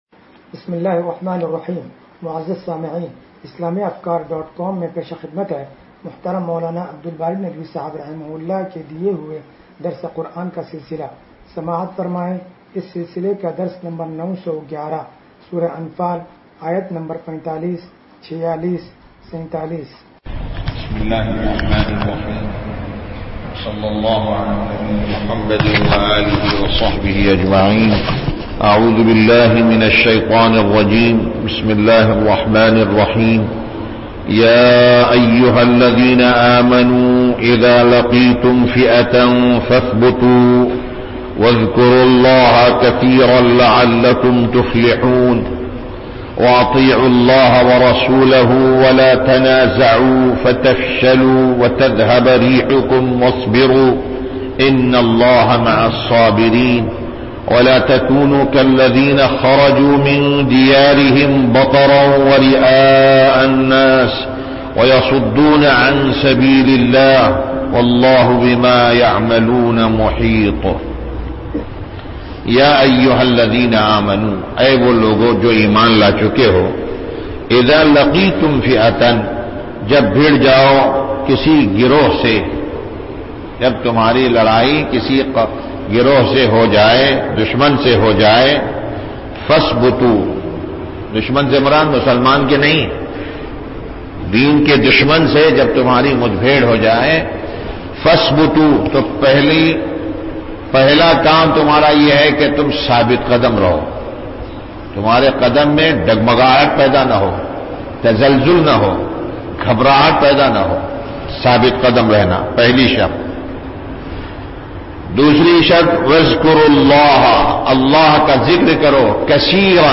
درس قرآن نمبر 0911
درس-قرآن-نمبر-0911.mp3